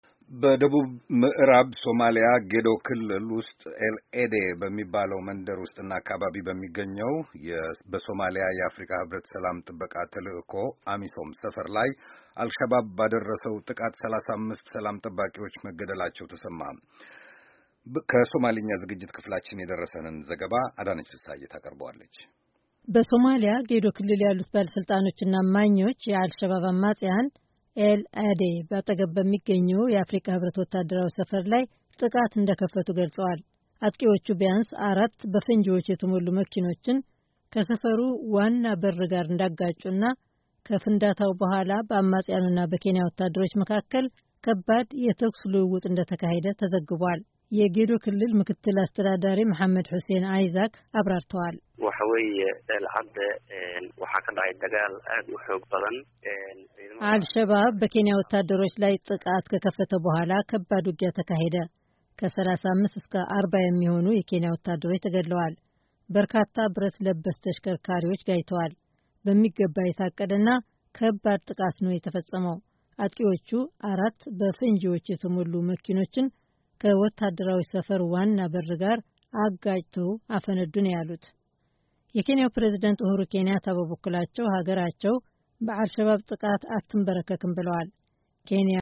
በደቡብ ሞዕራብ ሶማላያ ጌዶ ክልል ውስጥ ኤል-አዴ በተባለው መንደር በሚገኘው የአሚሶም ማለት በሶማልያ የአፍሪቃ ህብረት ሰላም ጥበቃ ተልእኮ ሰፈር ላይ ዐል ሸባብ ባደረሰው ጥቃት ቢያንስ 35 ሰላም ጠባቂዎች እንደተገደሉ ተዘግቧል። ዘገባውን ያዘጋጀው በአሜሪካ ድምጽ ሬድዮ የሶማልያ አገልግሎት ክፍል ሲሆን